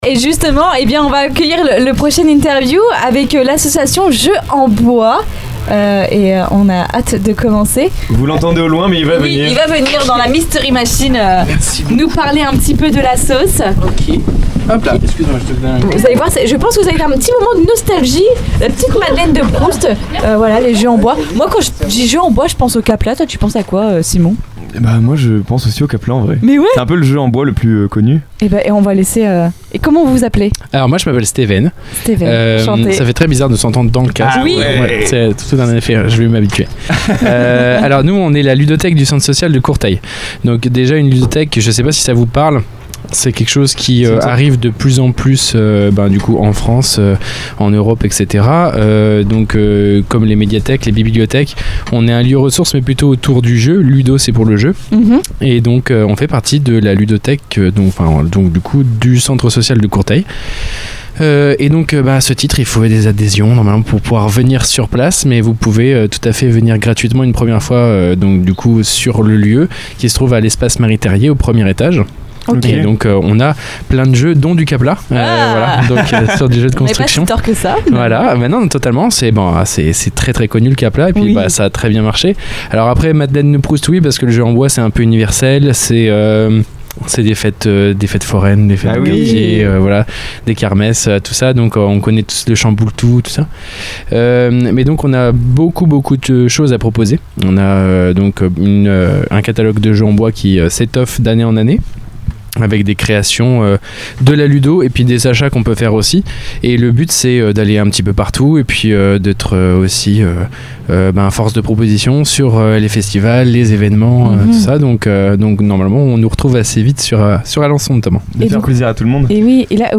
Un échange simple et passionné qui montre que la scène locale, ce sont aussi des acteurs culturels engagés au quotidien.